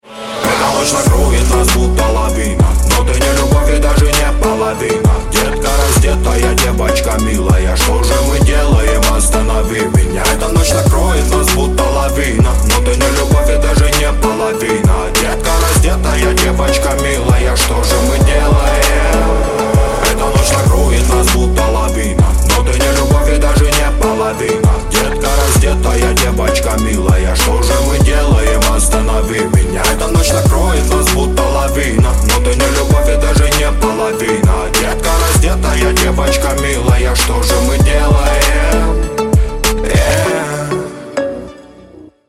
Рэп Хип-Хоп Рингтоны